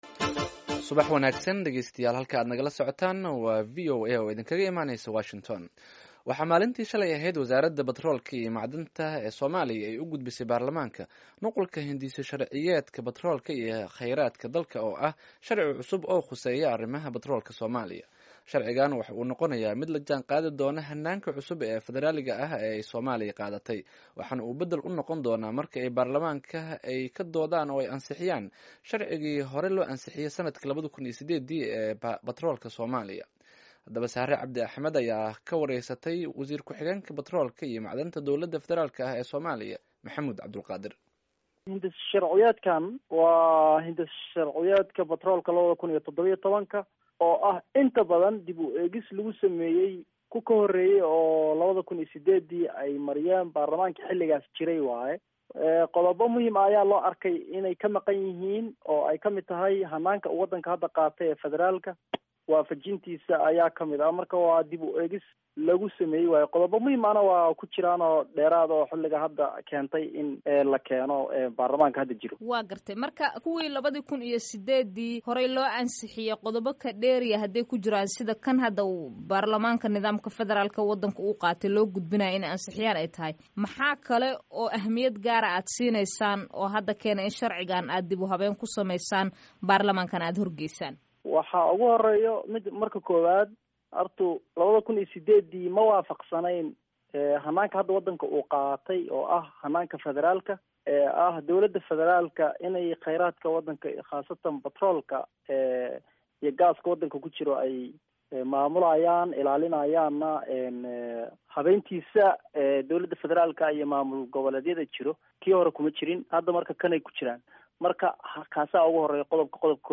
Dhageyso wareysiga wasiir ku xigeenka batroolka